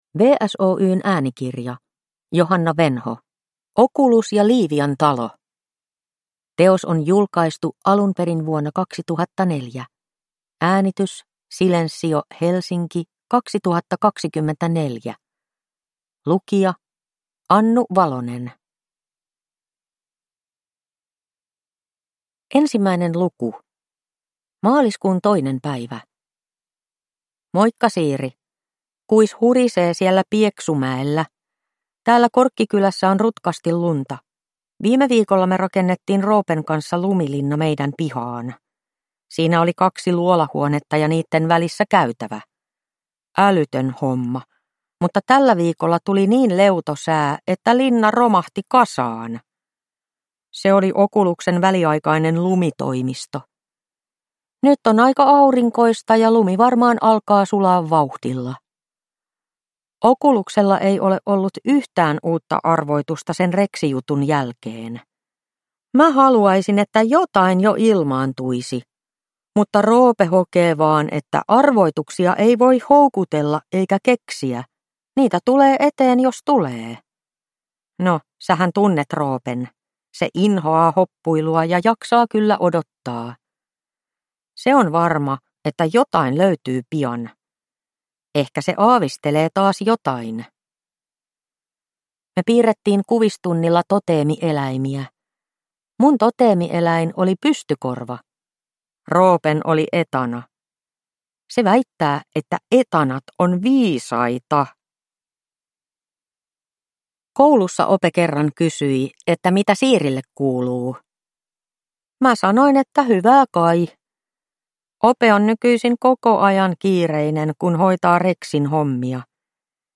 Okulus ja Livian talo – Ljudbok